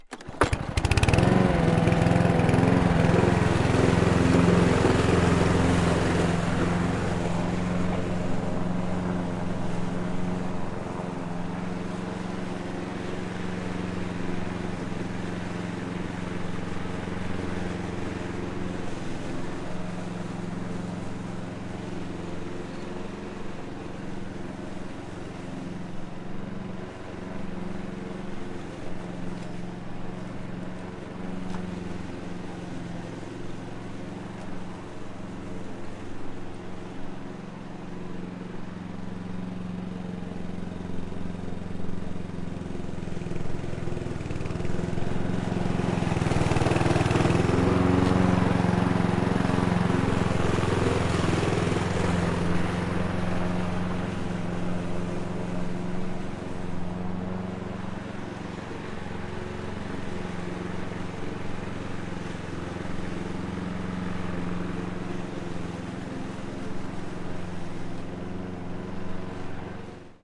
机械发动机 " 割草机发动机启动不同距离立体声MS
Tag: 修剪 机械 机械 反过来 切割 声音 割草机 效果 气体 开始 切割 草坪 修剪 发动机